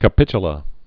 (kə-pĭchə-lə)